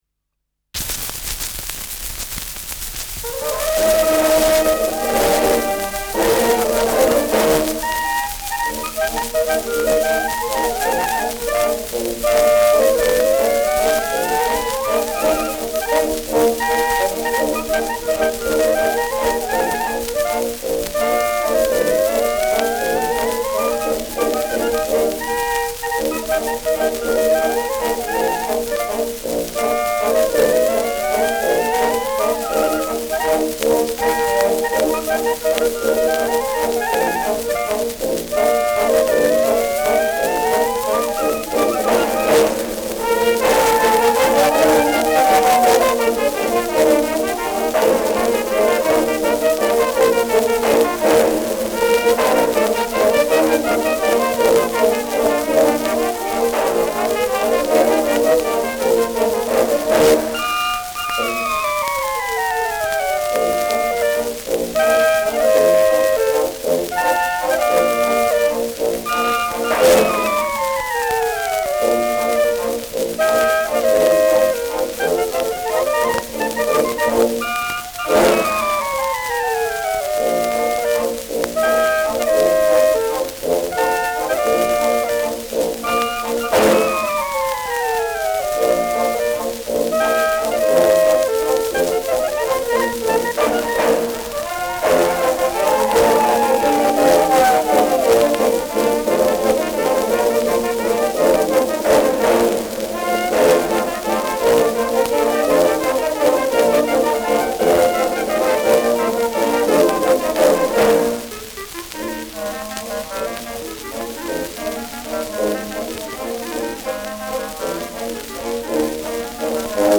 Schellackplatte
präsentes Rauschen